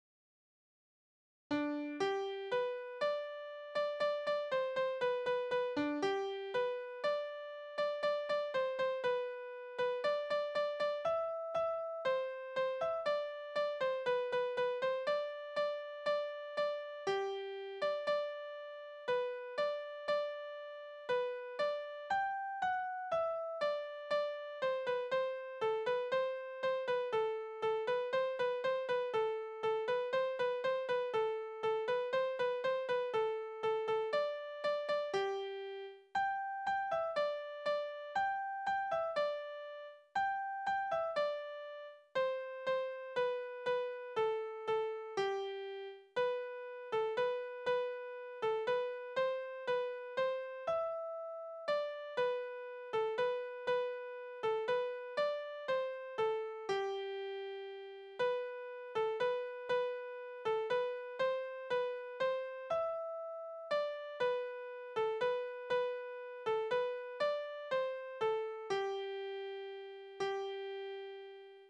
Tanzverse:
Tonart: G-Dur
Taktart: 4/4, 3/4
Tonumfang: Undezime
Besetzung: vokal
Anmerkung: Das Stück besteht aus zwei Teilen mit verschiedenen Taktarten. Der zweite Teil ist ein Walzer.